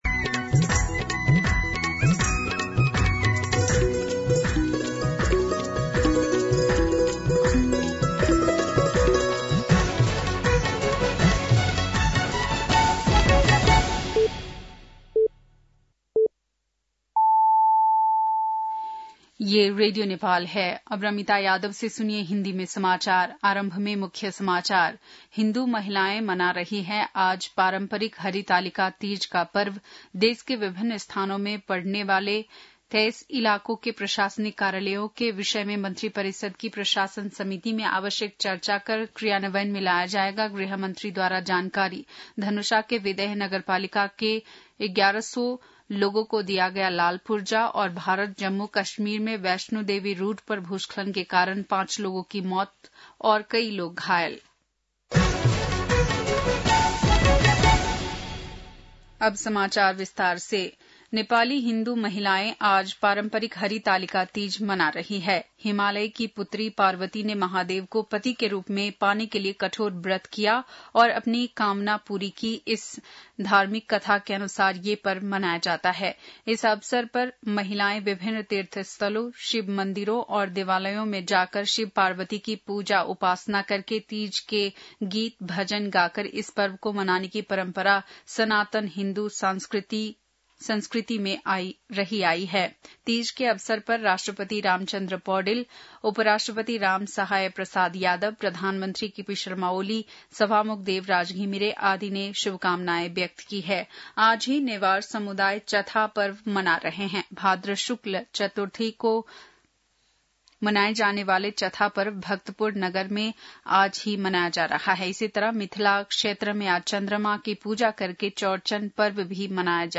बेलुकी १० बजेको हिन्दी समाचार : १० भदौ , २०८२